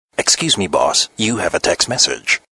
• Качество: 128, Stereo
на уведомление
голосовые
вежливые